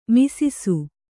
♪ misisu